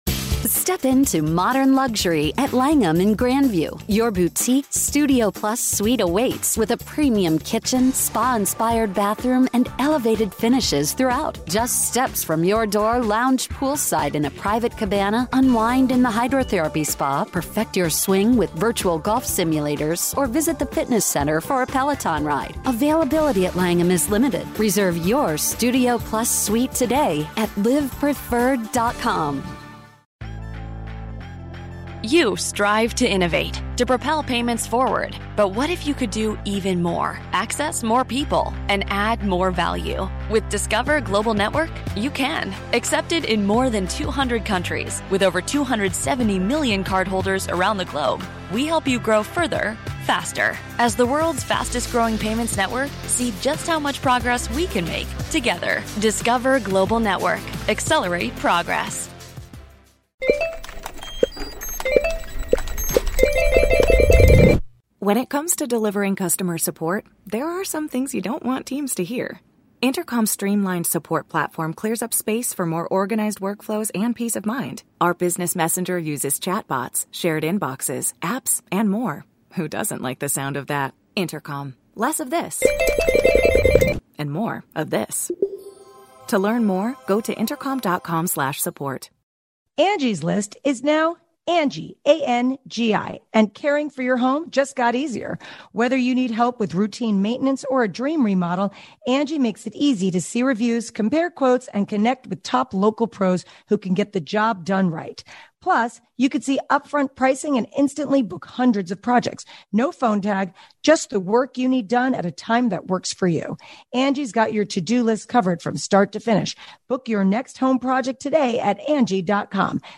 Stormy Daniels and Michael Cohen finally meet for the first time to discuss their shared history with Donald Trump, Michael Avenatti and what really happened in that Penthouse plus never before revealed details.